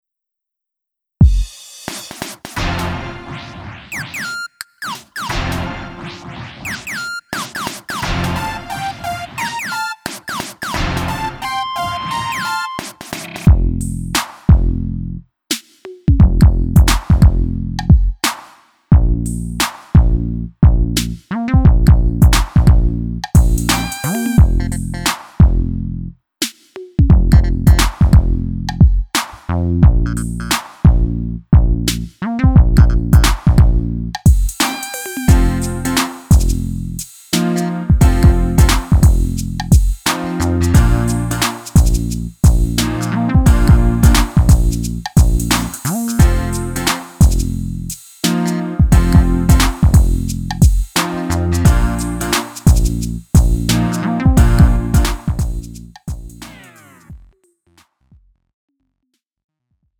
음정 원키 2:39
장르 가요 구분 Lite MR